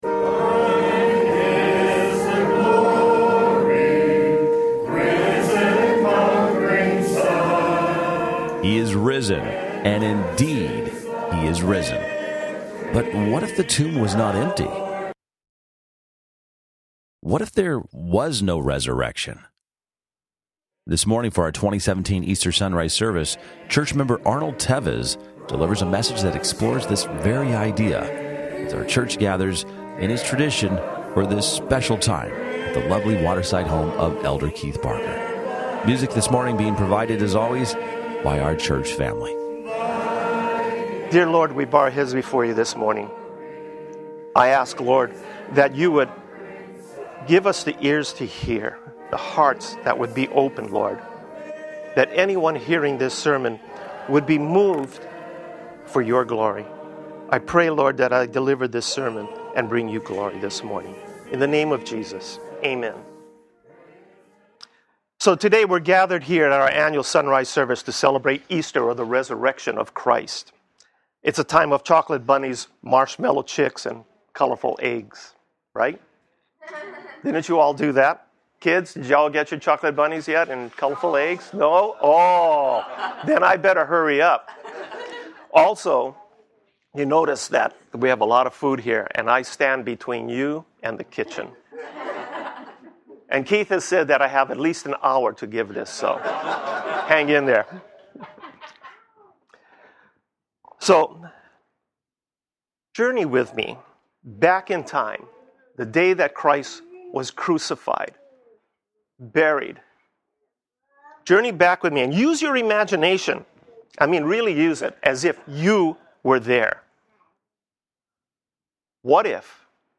Date: 04/16/2017, Easter Sunday